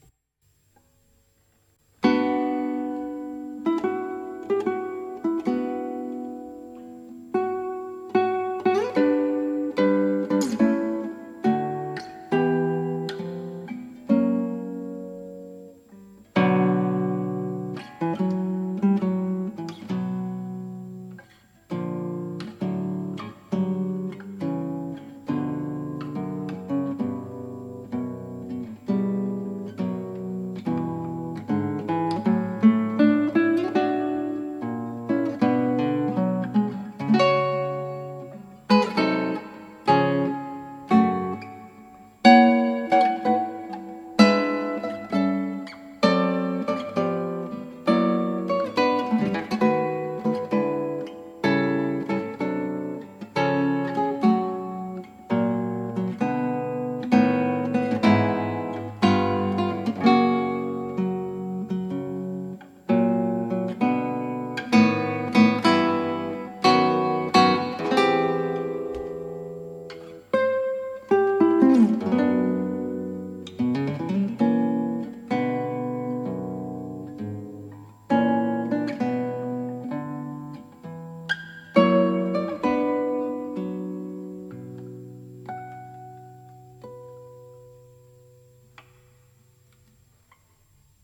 クラシックギター　ストリーミング　コンサート
なかなか荘厳な雰囲気です。